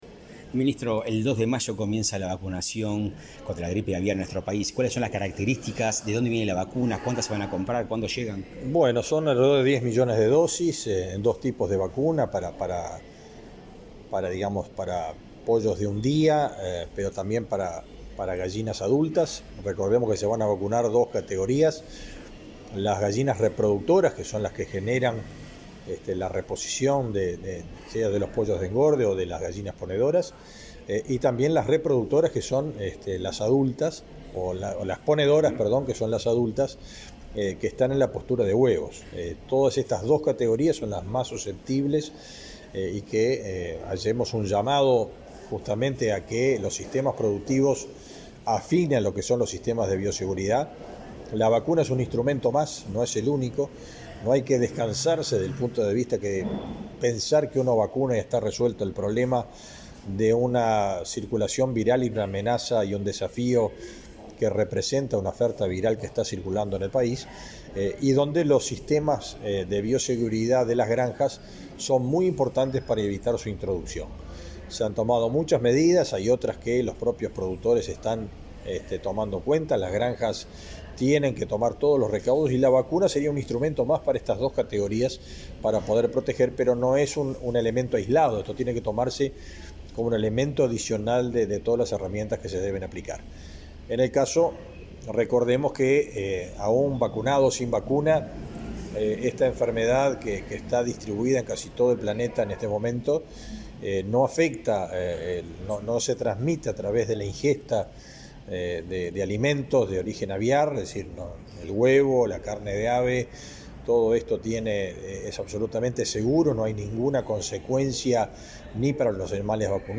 Entrevista al ministro de Ganadería, Fernando Mattos | Presidencia Uruguay